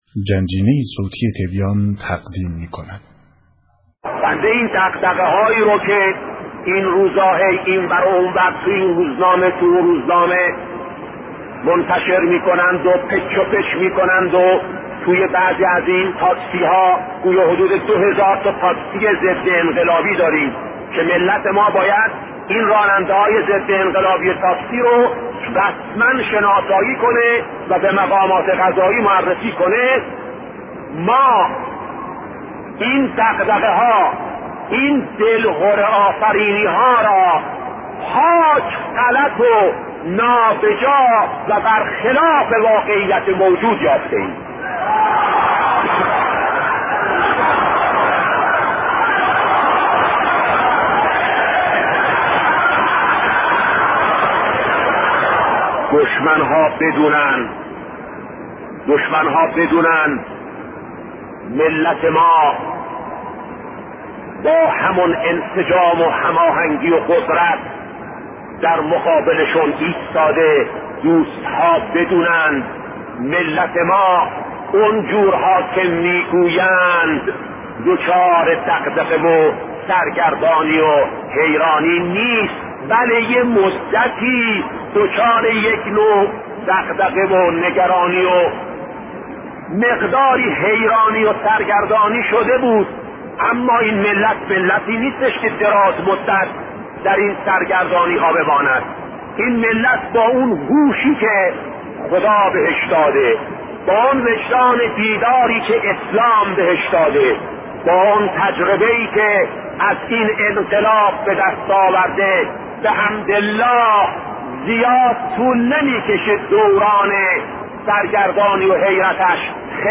دغدغه های انقلابیون؟- با صدای شهید بهشتی(ره)- بخش‌اول